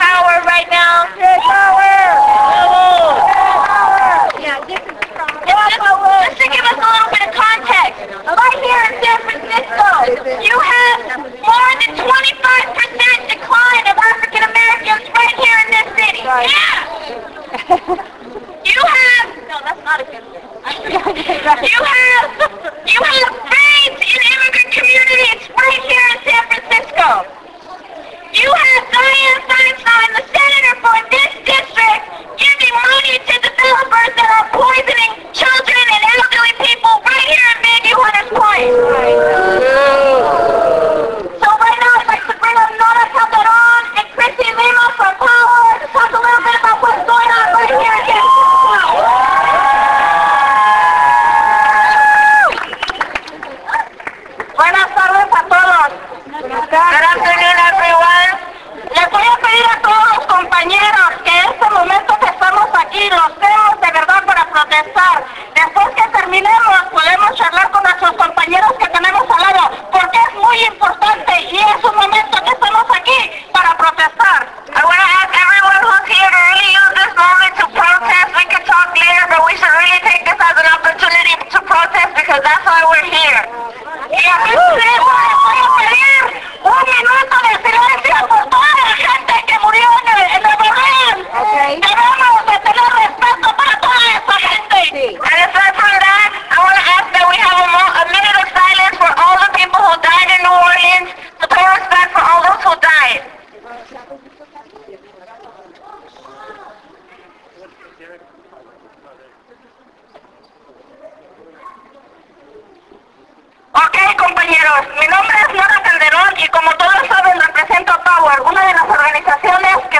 On top a hill overlooking San Francisco's bay, hundreds of us came together in an effort to hold Senator Dianne Feinstein accountable, stand in solidarity with people of the Gulf Coast and the world, mourn those lost, and celebrate resistance.